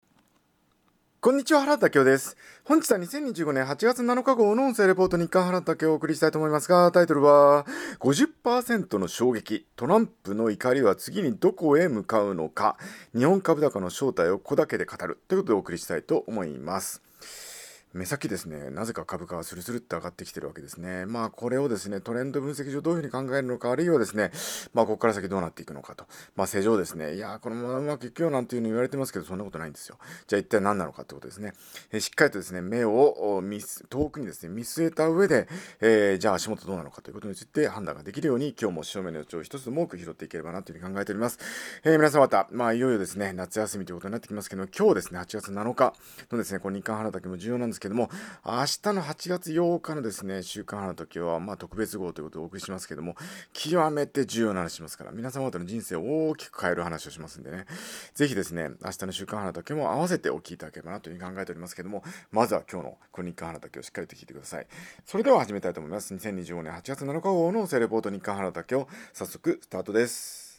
音声レポート